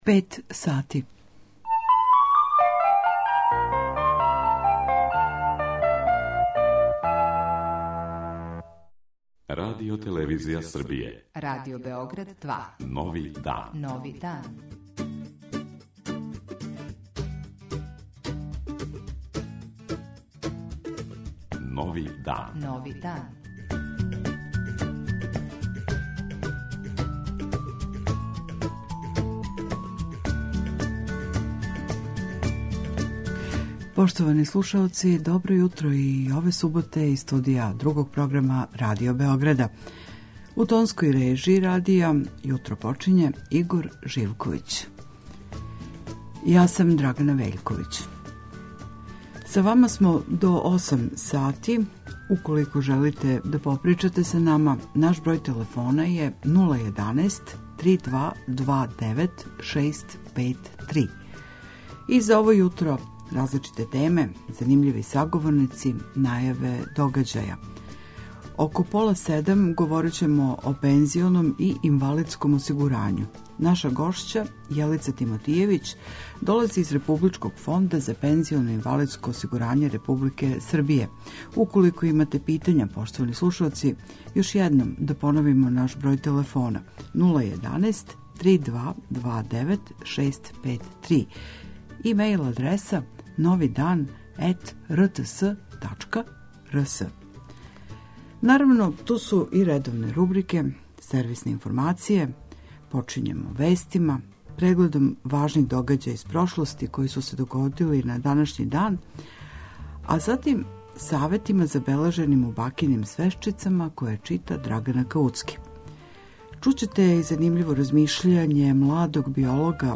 Јутарњи викенд програм магазинског типа